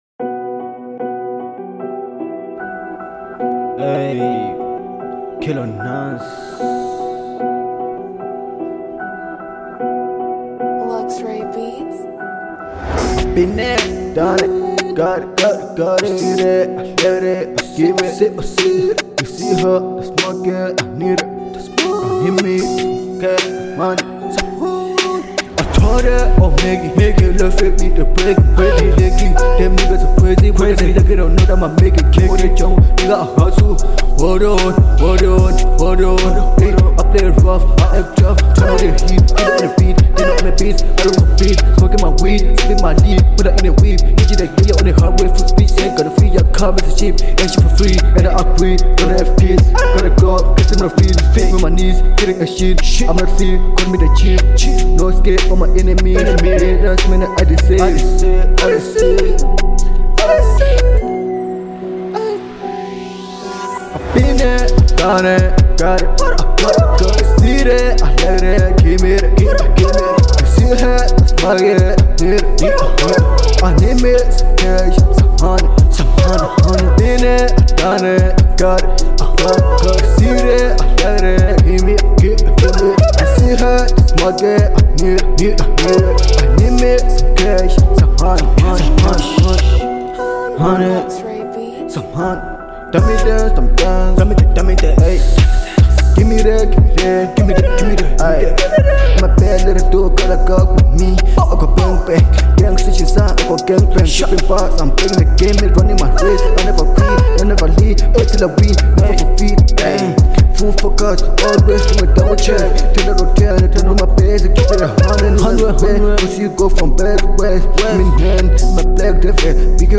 03:25 Genre : Trap Size